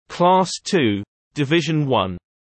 [klɑːs tuː dɪ’vɪʒn wʌn][клаːс туː ди’вижн уан]класс II, 1-й подкласс